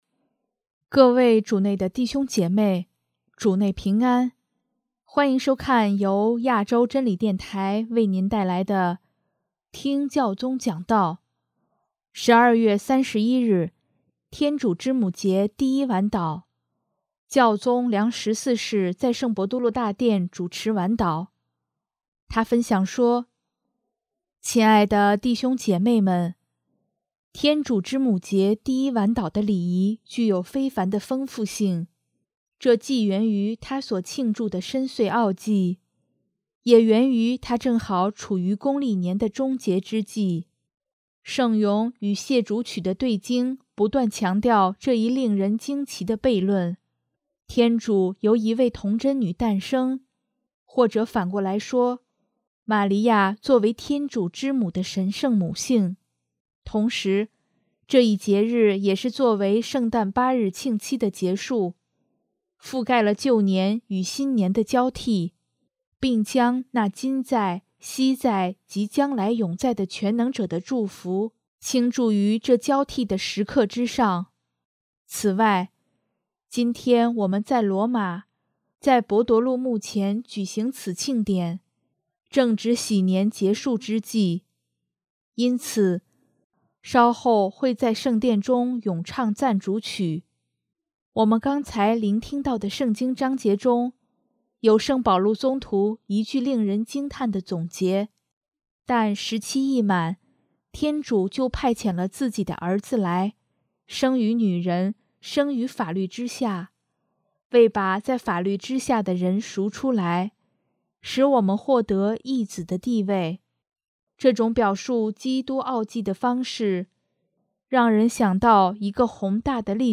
12月31日，天主之母节第一晚祷，教宗良十四世在圣伯多禄大殿主持晚祷，他分享说：